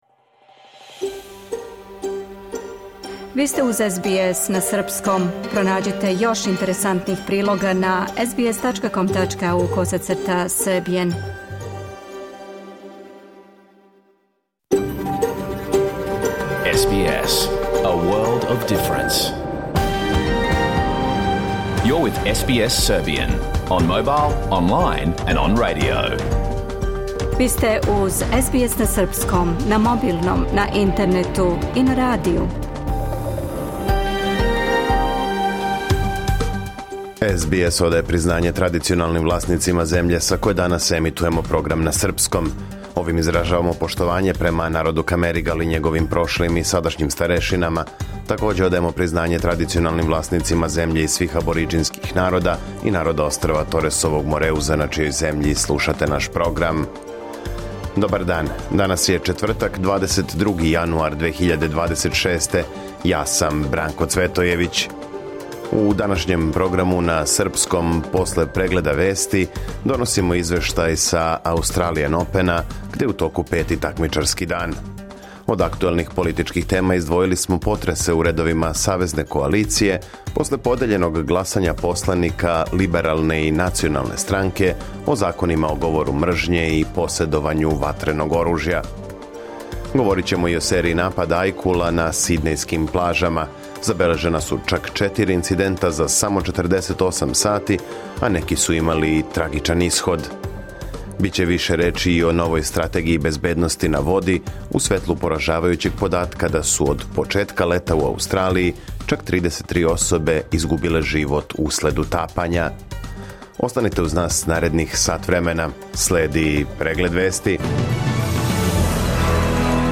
Програм емитован уживо 22. јануара 2026. године